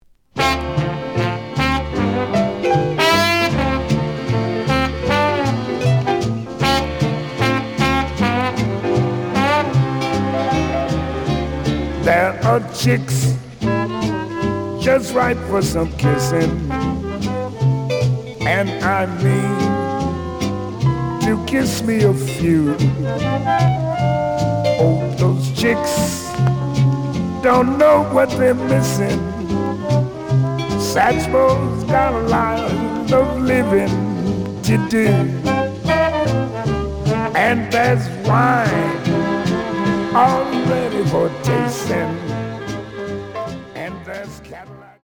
The audio sample is recorded from the actual item.
●Genre: Vocal Jazz
B side plays good.